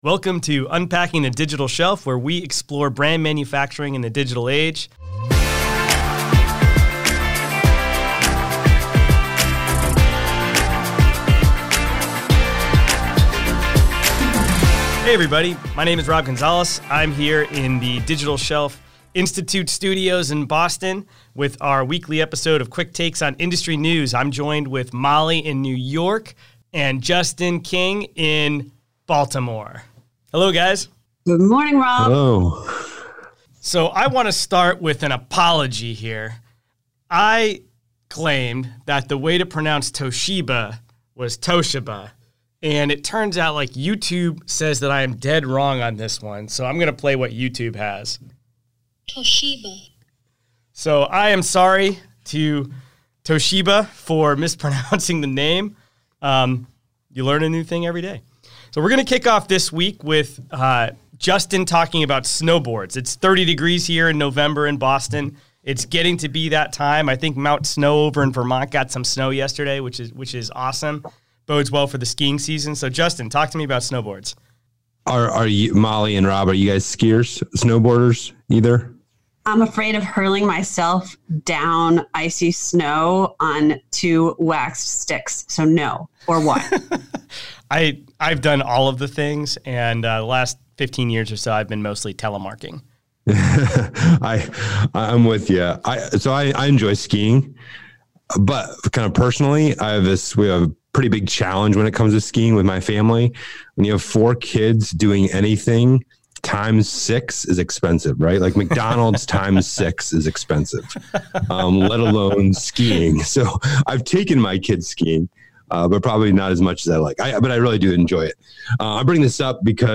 Marketing Silos Must Fall: Interview